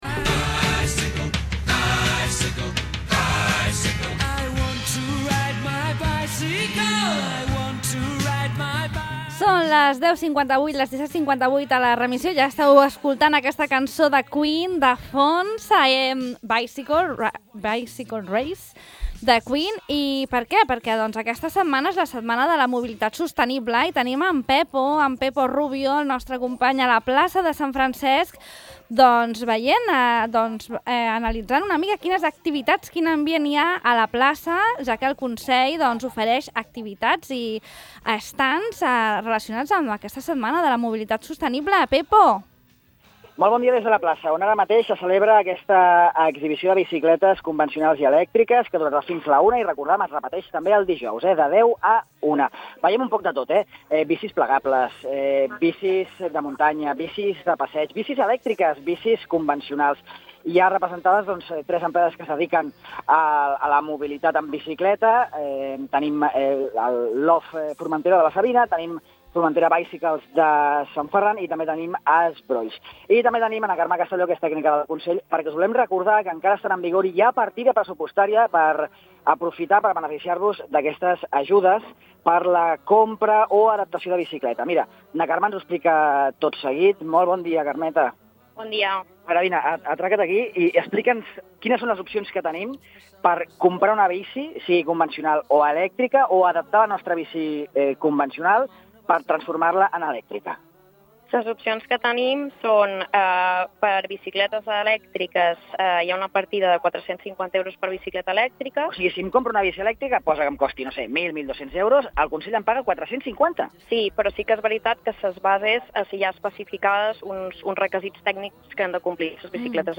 Informació sobre les ajudes de fins a 450 euros per a la compra d’una bicicleta elèctrica, descomptes en productes relacionats amb la bici i la possibilitat de provar tota mena de bicicletes. Aquestes són alguns dels atractius que hem trobat a l’exhibició que avui s’ha muntat a la plaça de la Constitució de Sant Francesc i que torna a desplegar-se dijous, al mateix lloc, de 10 a 13 hores, en el marc de les activitats de la Setmana Europea de la Mobilitat Sostenible.